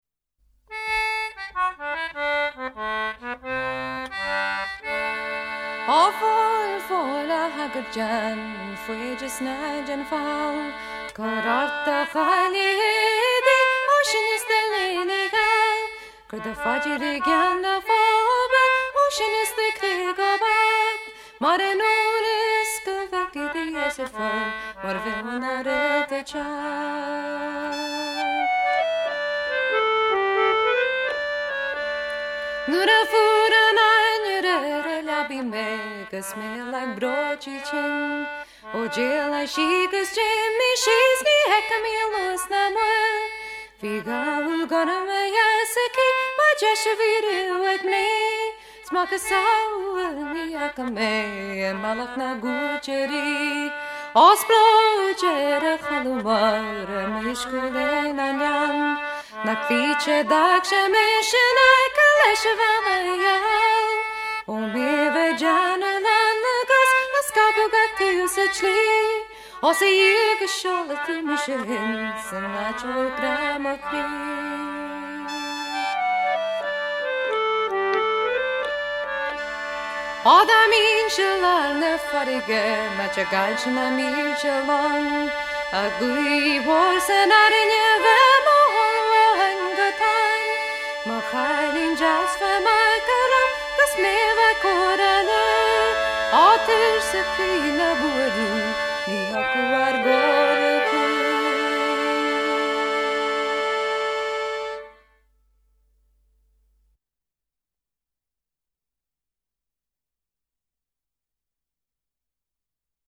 Whistle
Fiddle
Concertina
Guitar, Harmonium
Harmony Vocals